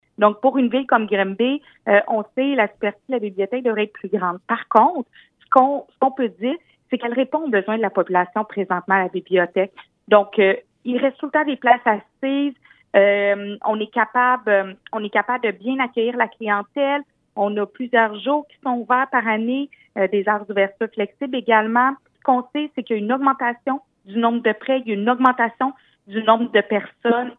Nouvelles
La mairesse de Granby, Julie Bourdon, est revenue lors de sa chronique mensuelle, ce mardi, sur les résultats d’un portrait peu flatteur à l’endroit de la bibliothèque Paul-O. Trépanier.